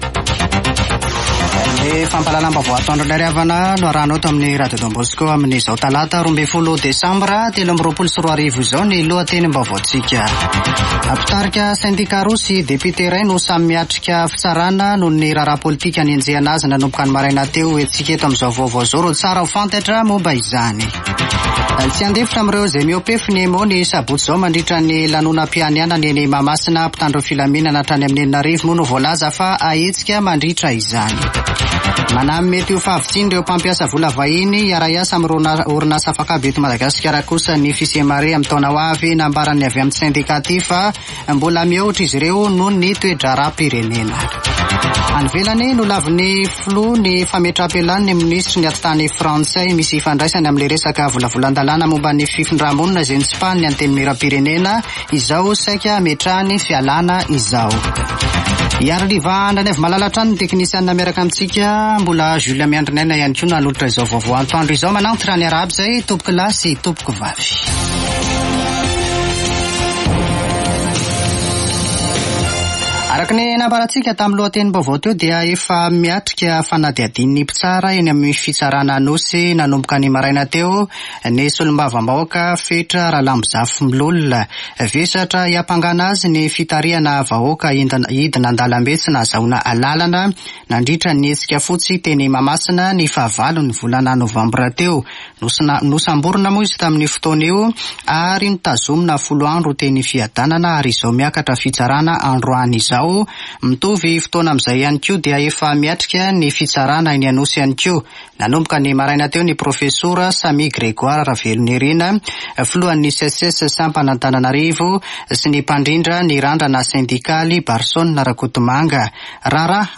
[Vaovao antoandro] Talata 12 desambra 2023